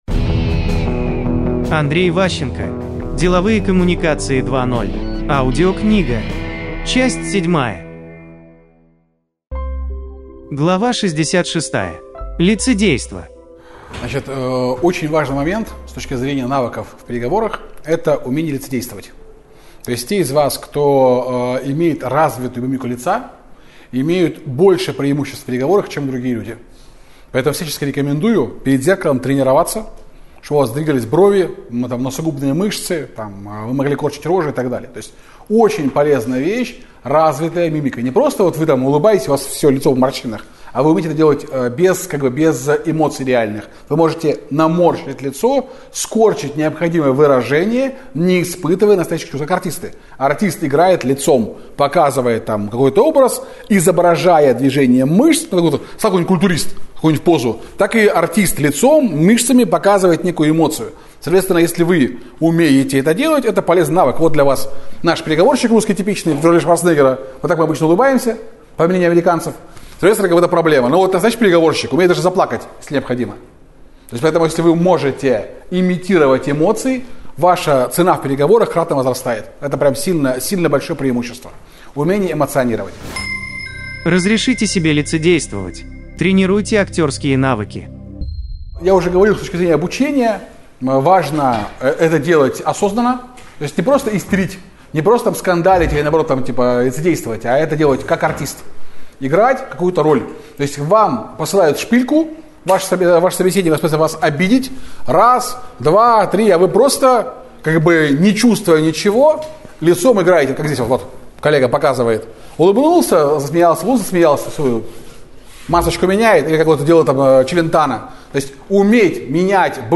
Аудиокнига Деловые коммуникации 2.0. Часть 7 | Библиотека аудиокниг